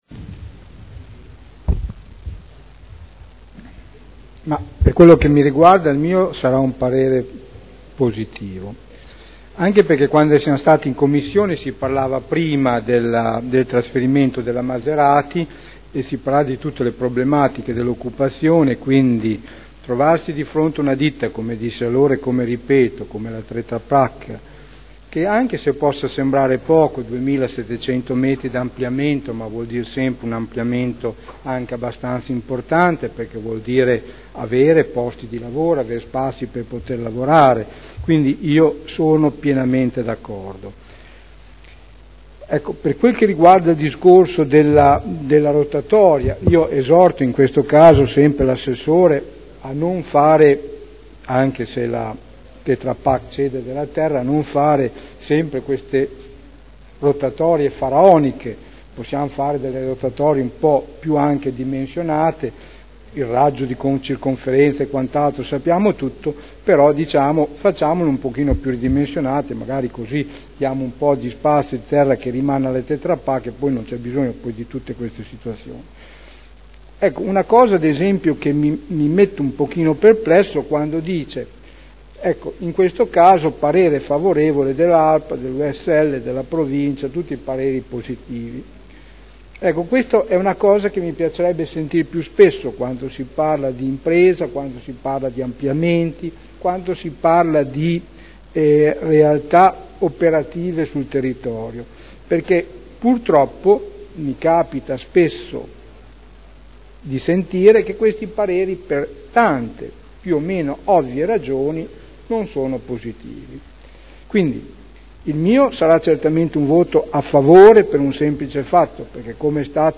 Sergio Celloni — Sito Audio Consiglio Comunale